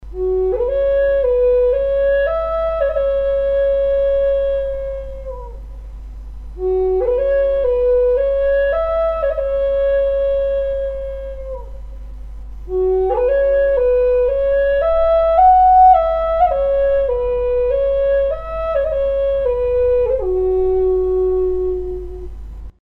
Флейта-посох F# (Walking Stick Flute)
Длина (см): 133
Флейта имеет пять игровых отверстий. Строй стандартная минорная пентатоника. Несмотря на размер, флейта звучит в среднем диапазоне. Изготовлена из достаточно крепкого вида тростника.